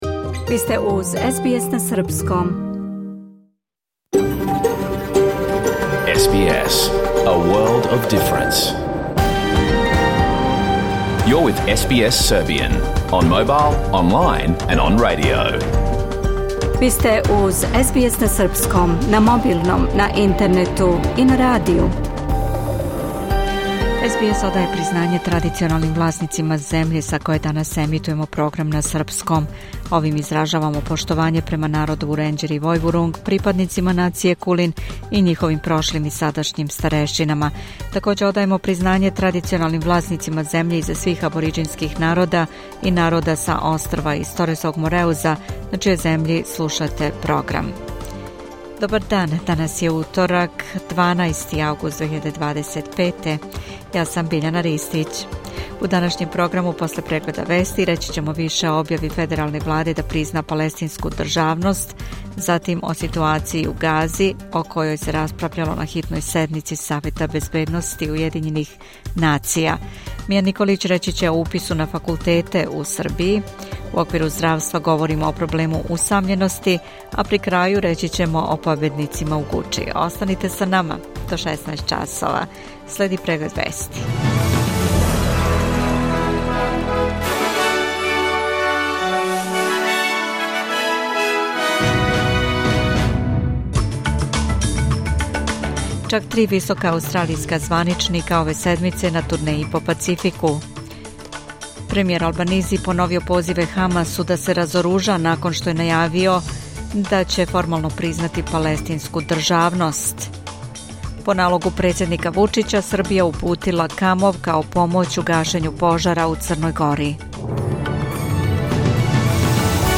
Програм емитован уживо 12. августа 2025. године